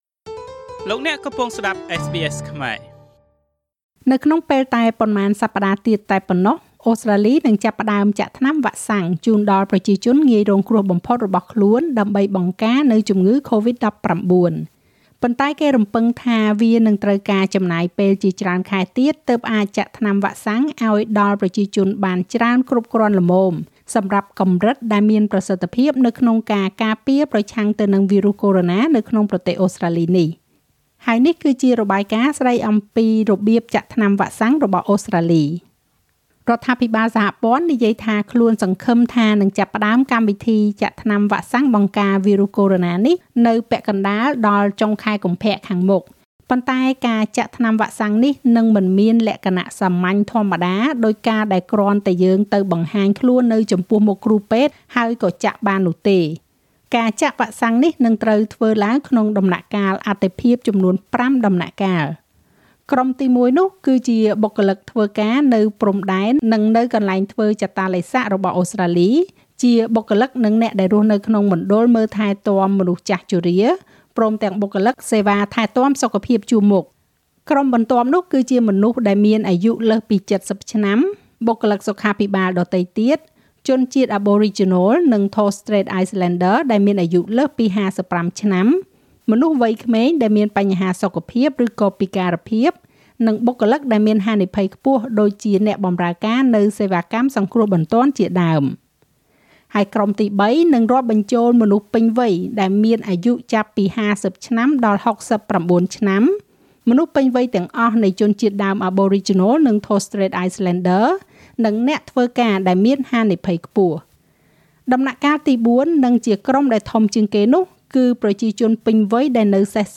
ហើយនេះជារបាយការណ៍ស្តីពីរបៀបចាក់ថ្នាំវ៉ាក់សាំងរបស់អូស្រ្តាលី។